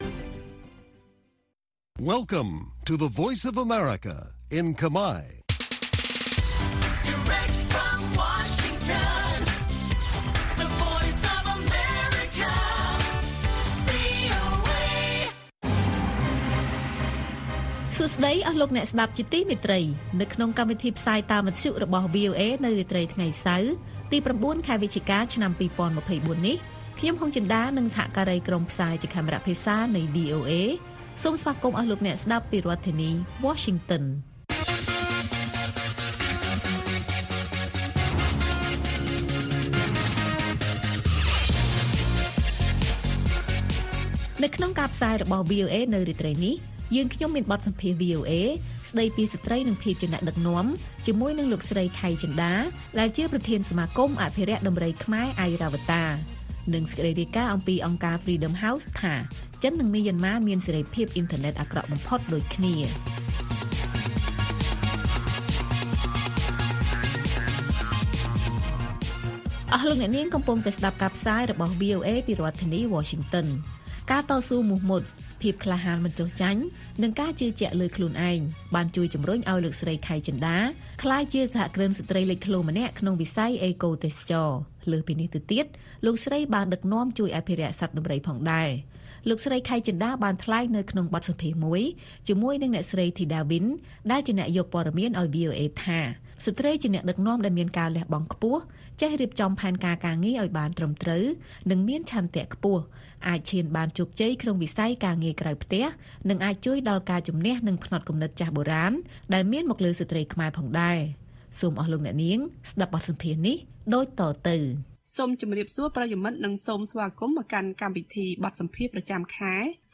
ព័ត៌មានពេលរាត្រី
បទសម្ភាសន៍ VOA ស្តីពី «ស្រ្តីនិងភាពជាអ្នកដឹកនាំ»